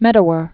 (mĕdə-wər), Sir Peter Brian 1915-1987.